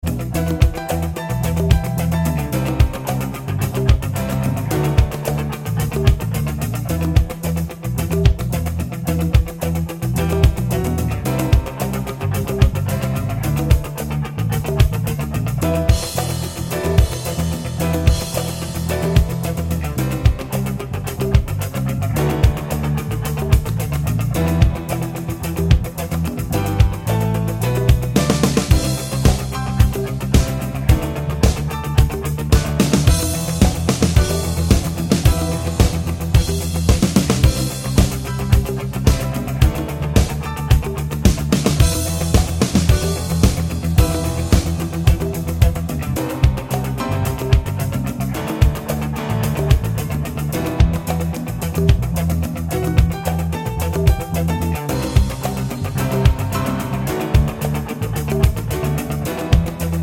no Backing Vocals Soft Rock 5:44 Buy £1.50